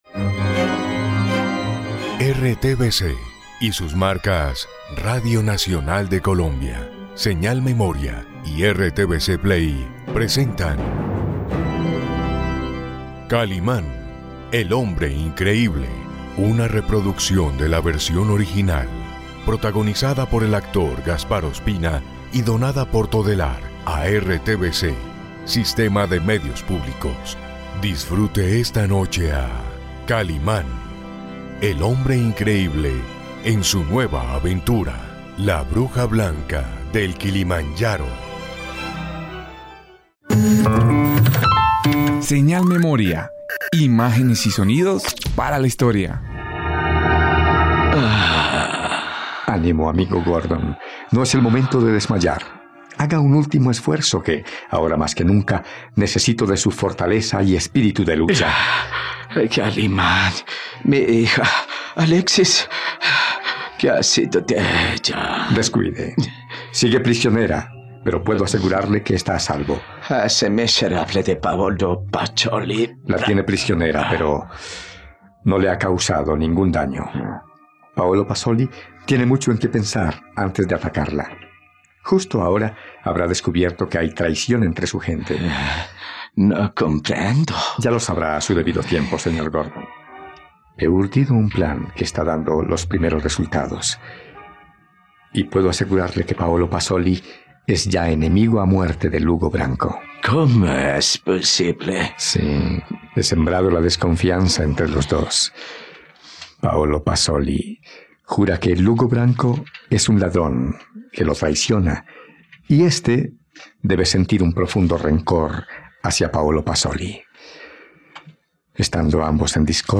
Revive gratis la fascinante radionovela de Kalimán en RTVCPlay